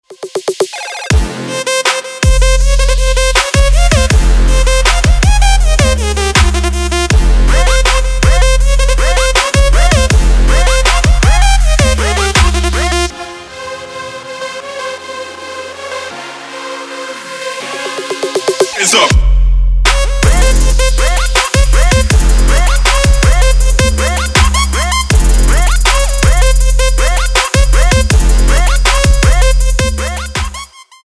без слов
Trap
Bass